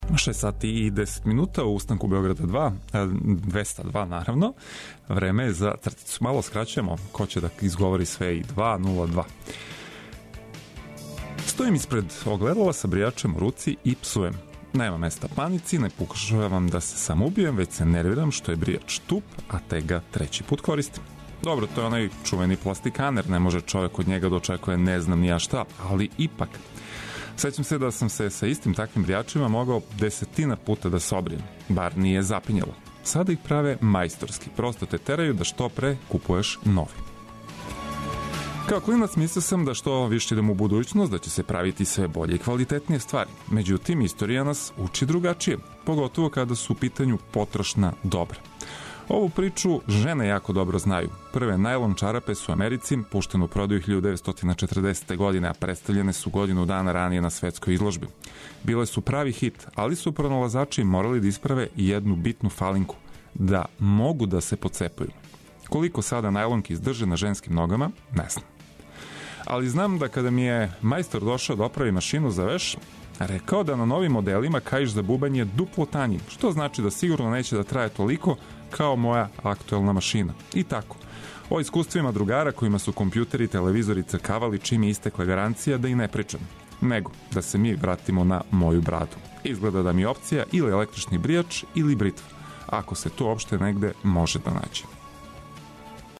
Овога јутра орасположићемо вас графитима и Кваком, а чућете и Кратке резове. Уз уобичајени сет информације и прогнозе за данашњи дан, биће ту и музика за лакше напуштање топлог кревета!